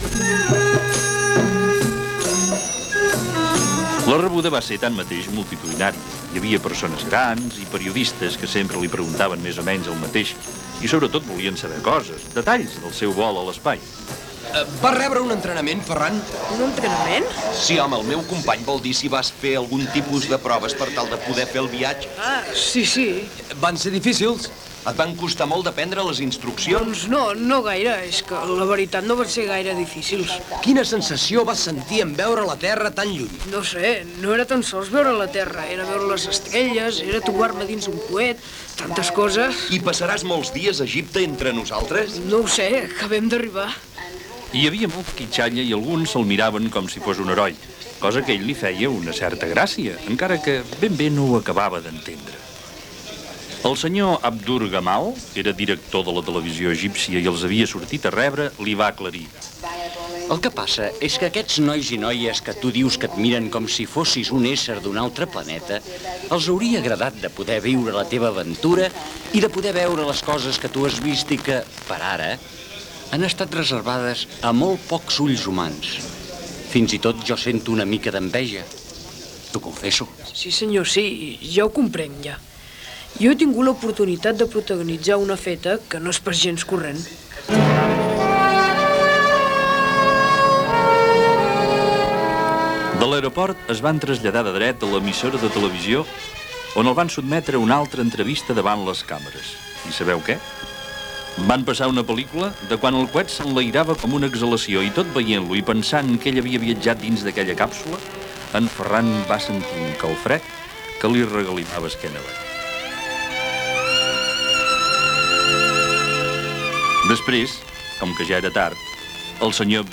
Fragment d'un episodi i sintonia de sortida
Ficció